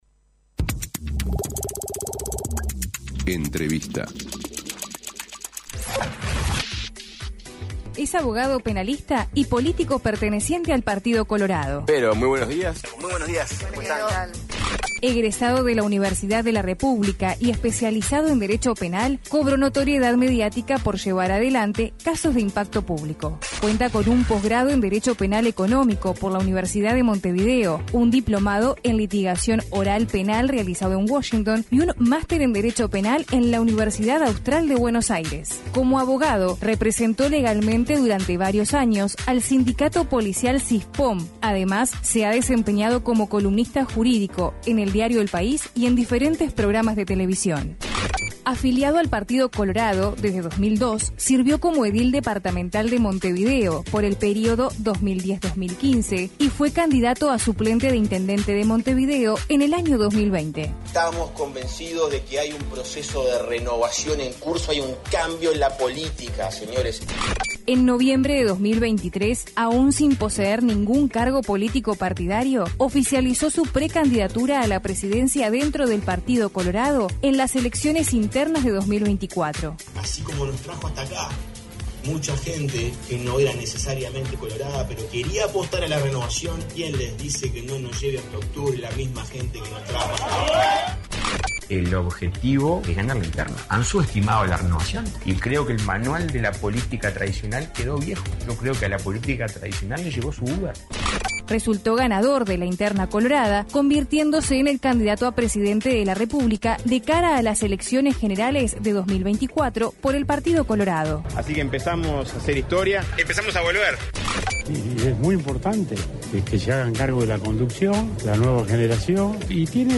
Entrevista a Andrés Ojeda